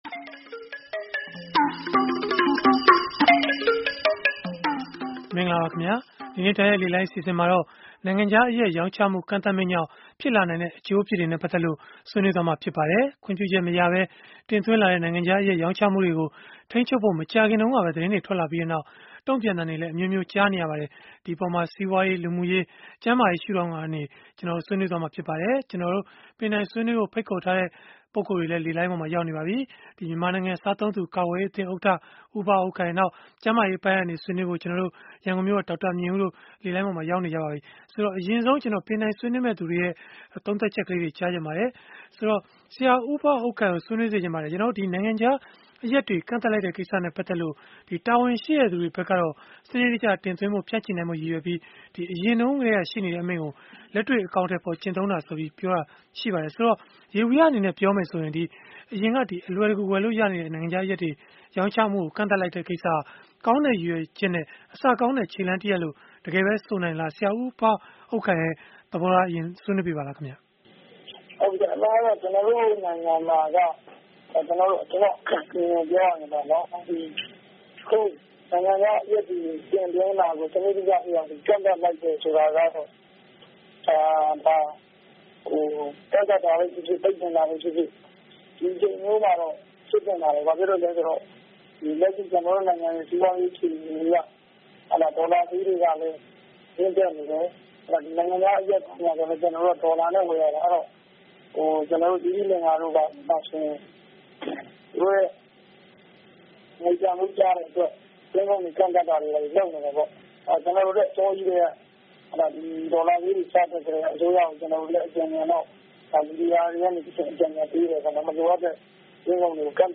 ဗွီအိုအေမြန်မာပိုင်းရဲ့ စနေနေ့ည တိုက်ရိုက်လေလှိုင်း အစီအစဉ်မှာ